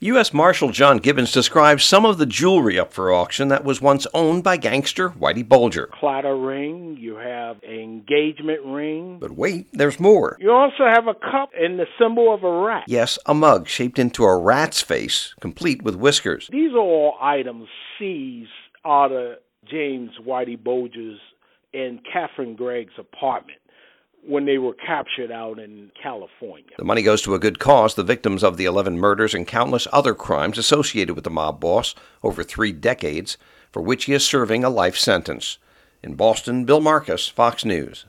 U.S. MARSHALL JOHN GIBBONS DESCRIBES ONE OF THE ITEMS UP FOR AUCTION.